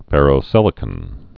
(fĕrō-sĭlĭ-kən, -kŏn)